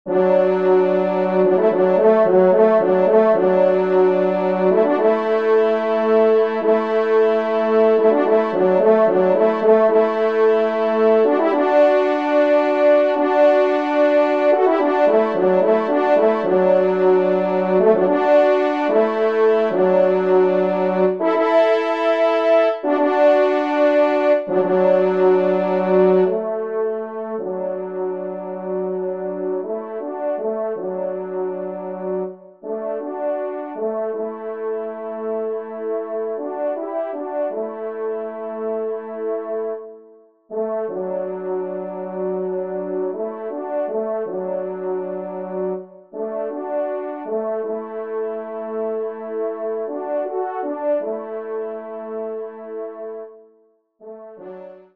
Genre :  Divertissement pour Trompes ou Cors en Ré
2e Trompe